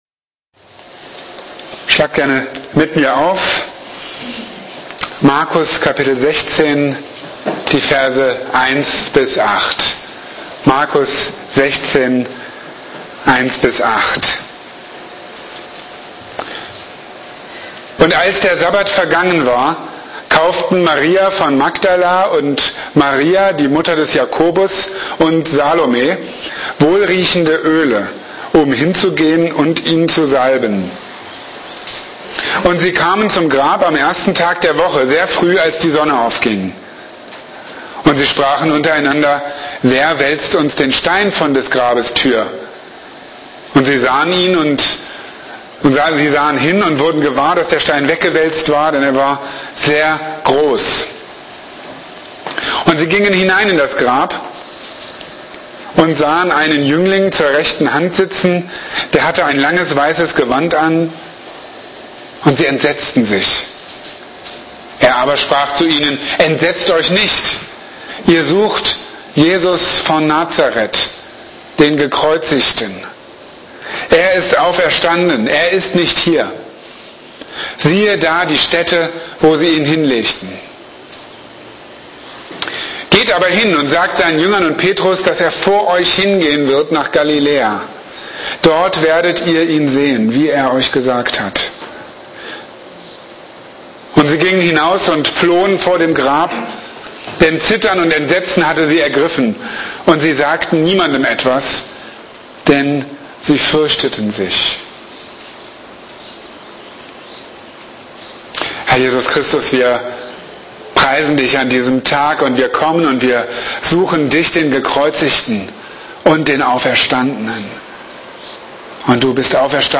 Er ist auferstanden, er ist nicht hier! | Marburger Predigten